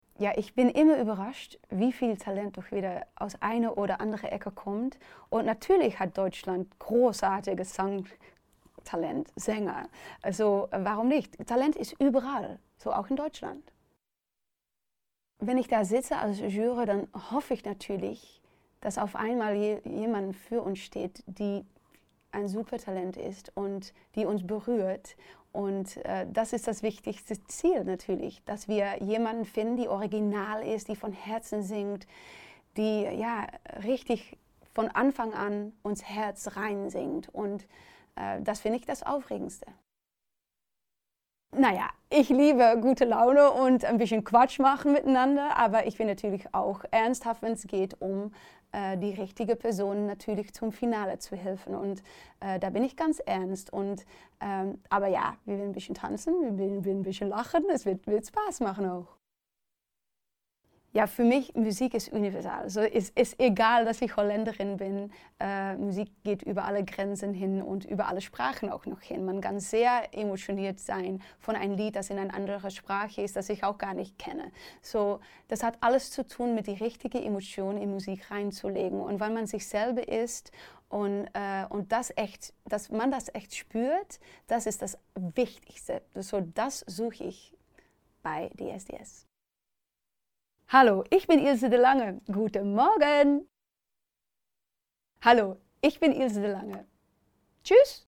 Ilse DeLange im RTL-O-Ton